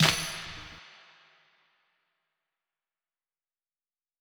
MASSIVECLAP.wav